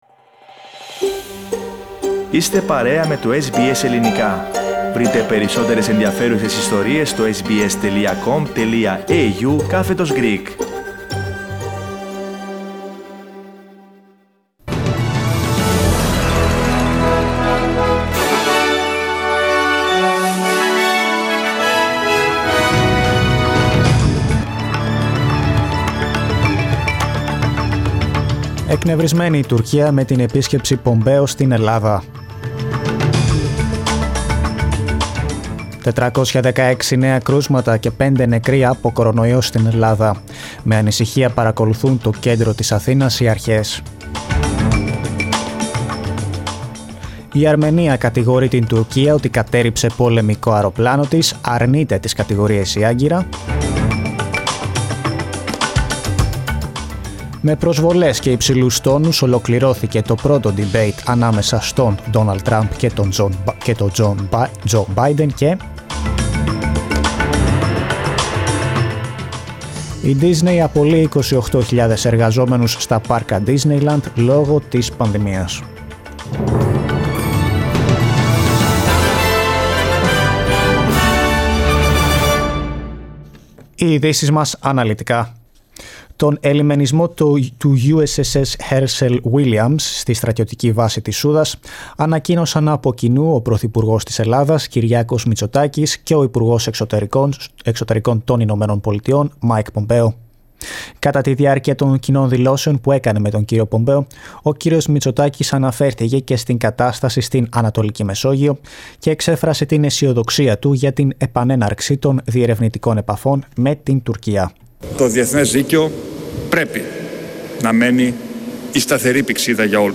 News from Australia, Greece, Cyprus and the world, in the news bulletin of Wednesday 30 of September.